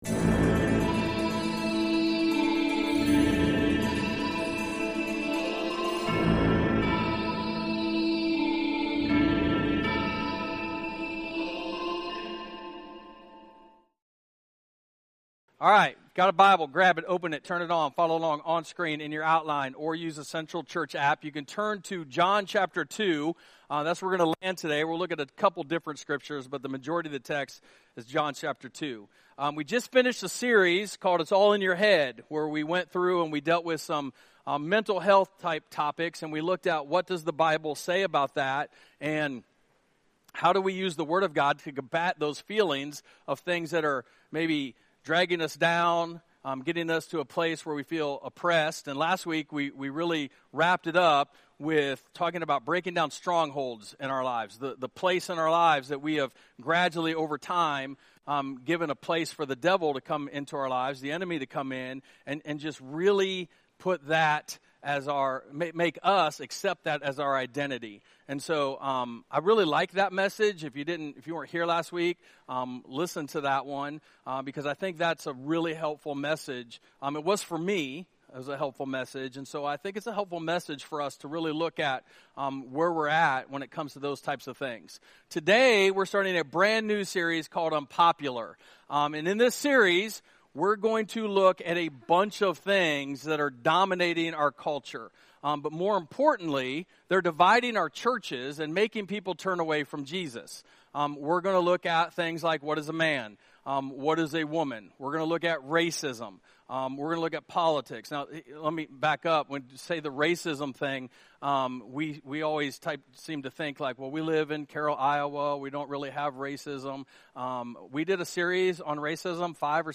We kicked off our Unpopular sermon series Sunday with "A Godly Man" - inspired by John 2:13-17.